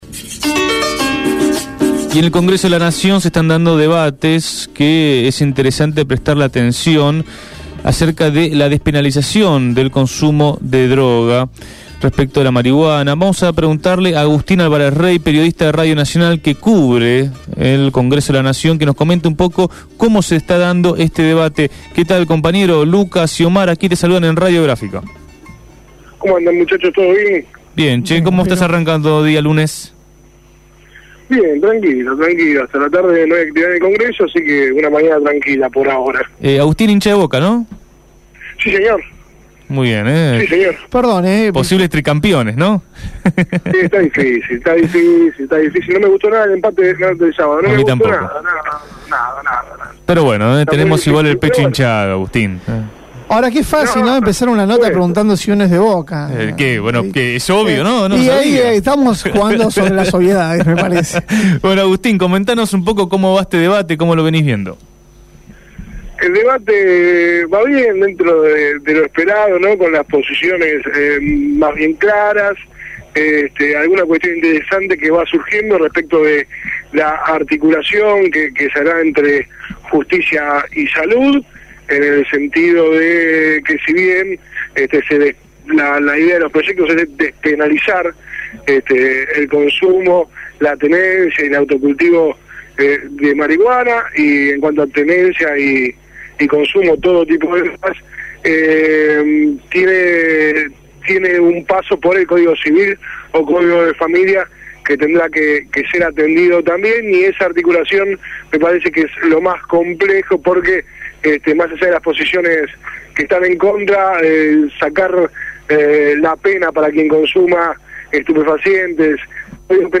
Despenalización: Informe desde el Congreso